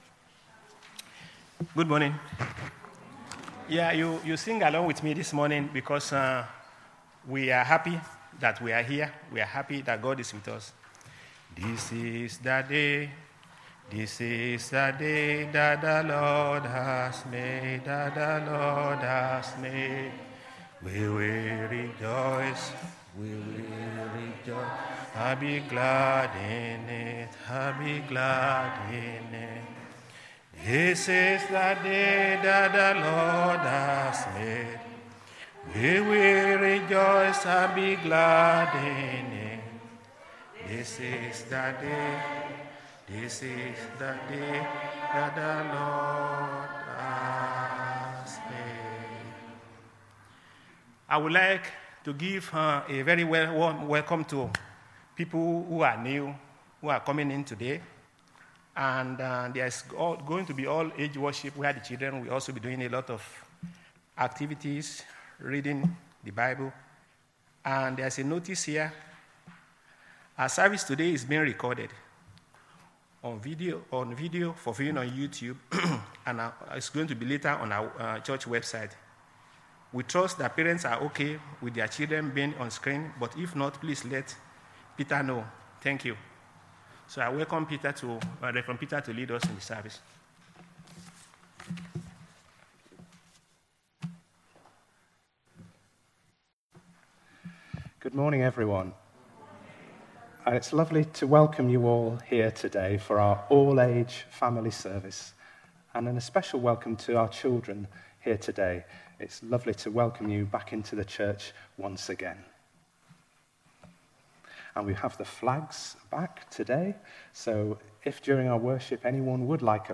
An audio version of the talk is also available.